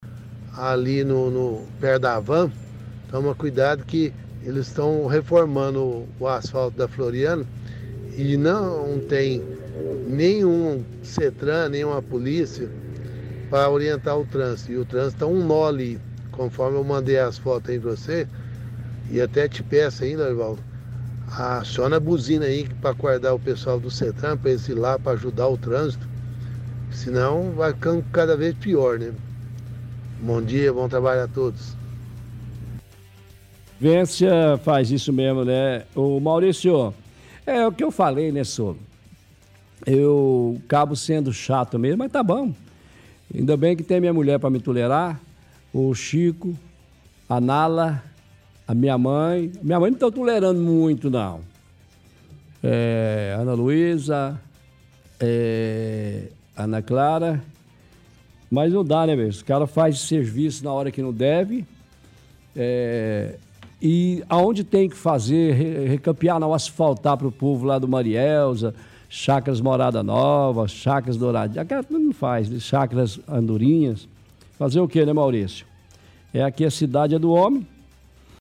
– Ouvinte reclama de falta de sinalização nas obras que a SETTRAN está realizando no bairro Umuarama, diz que não há agentes para auxiliar.